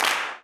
CLAP163.wav